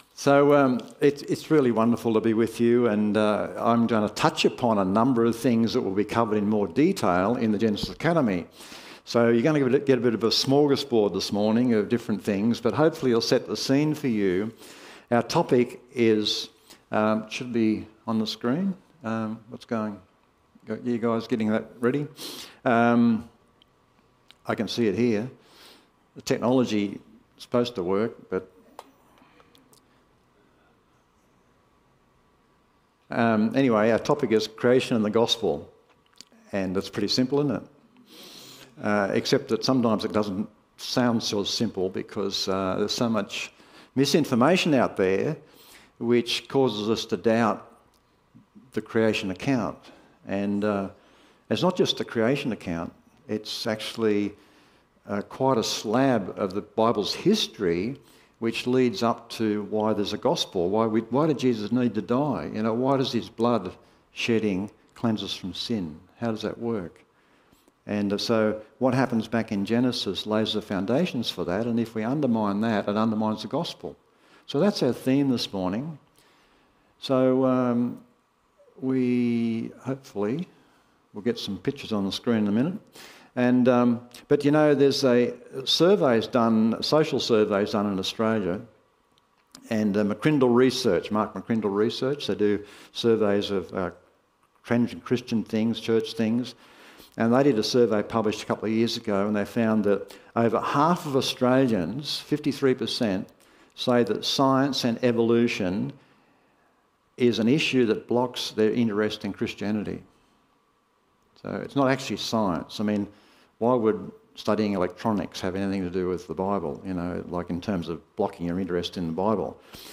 Visiting guest speaker
sermon